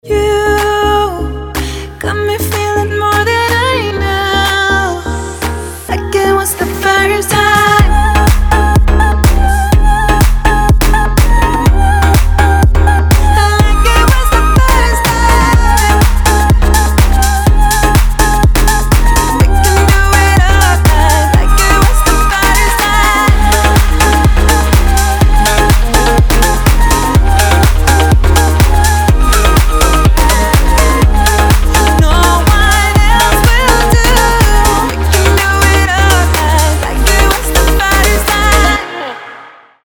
• Качество: 320, Stereo
dance
future house
club
энергичные
красивый женский голос
красивый женский вокал
house